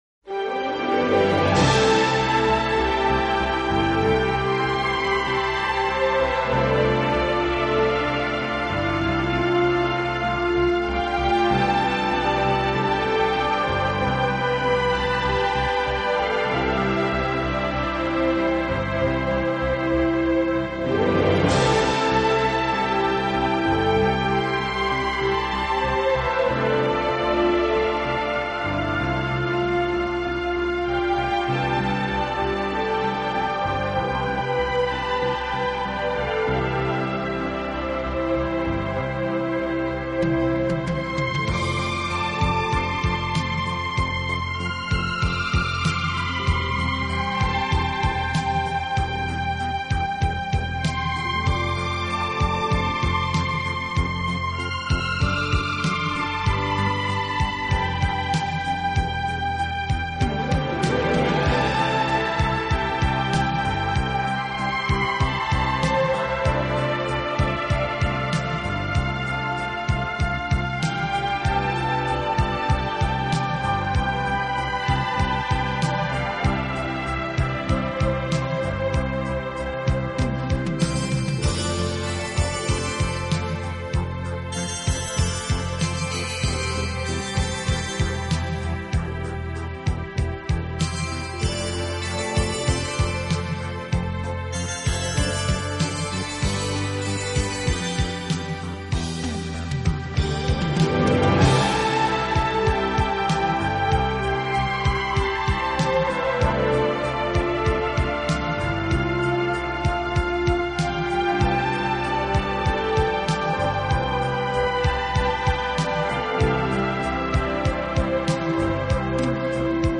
【轻音乐】
【顶级轻音乐】
录制方式：AAD